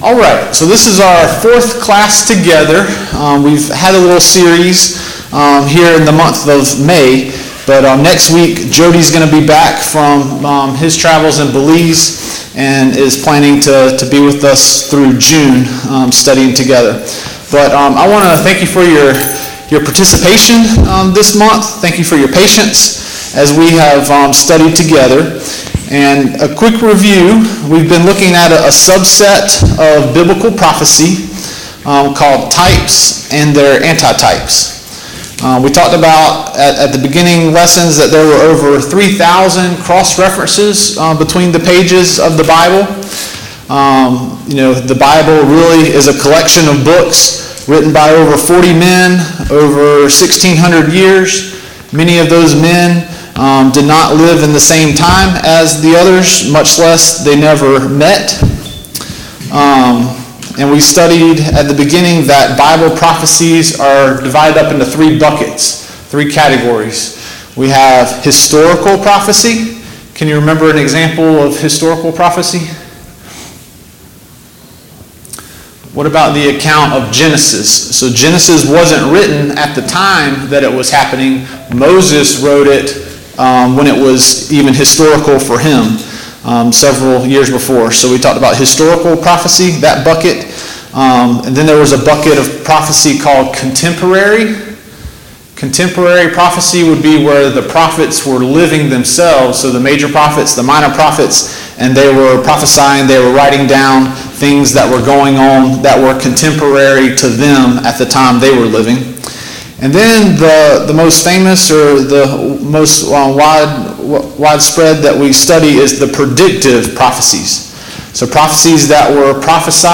Service Type: Sunday Morning Bible Class Topics: Jesus Christ , Salvation , The Tabernacle